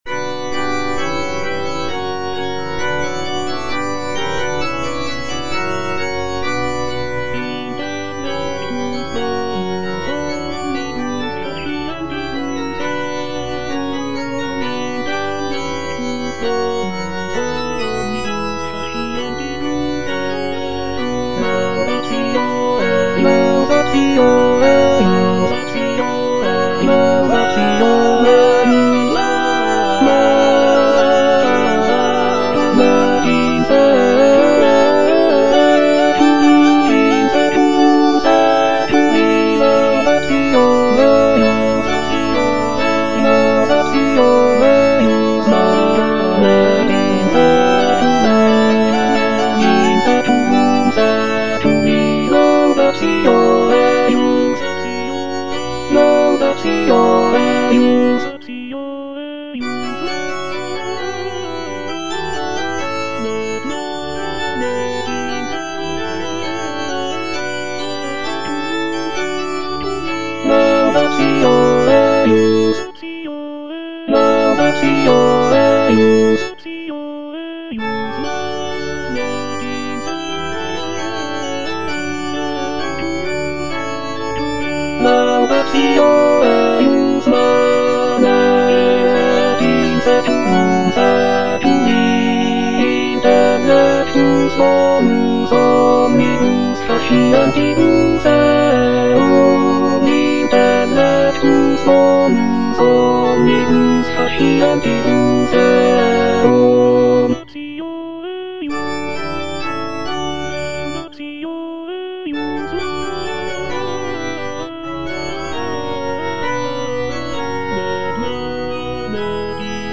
M.R. DE LALANDE - CONFITEBOR TIBI DOMINE Intellectus bonus omnibus (baritone) - Bass (Emphasised voice and other voices) Ads stop: auto-stop
It is a setting of the Latin text from Psalm 111, expressing gratitude and praise to the Lord. Lalande's composition features intricate polyphony, lush harmonies, and expressive melodies, reflecting the Baroque style of the period. The work is known for its grandeur and solemnity, making it a popular choice for performances in churches and concert halls.